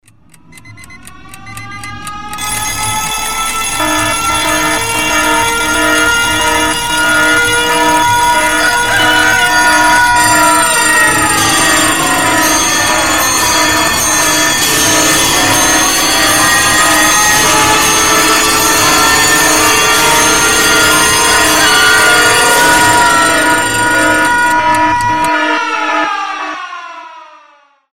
Очень громкий будильник - Паника с сиреной